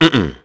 Crow-Vox-Deny.wav